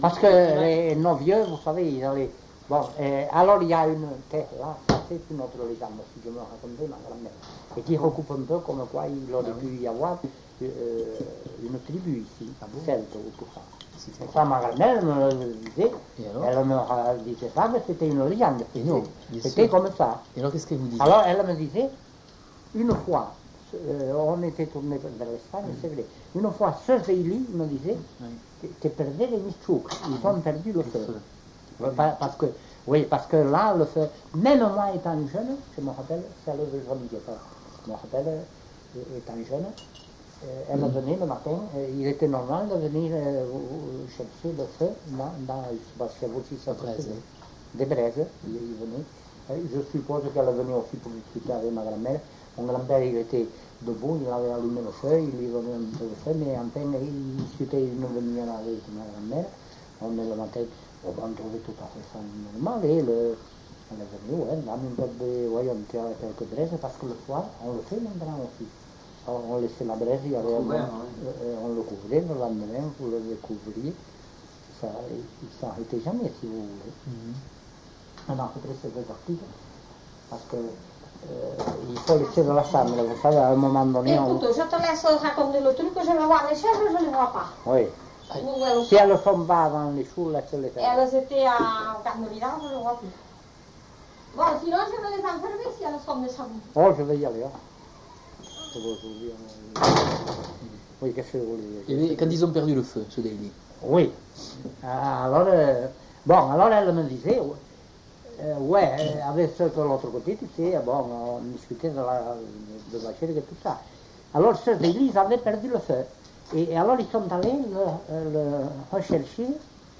Aire culturelle : Couserans
Genre : conte-légende-récit
Effectif : 1
Type de voix : voix d'homme
Production du son : parlé
Classification : récit légendaire
Ecouter-voir : archives sonores en ligne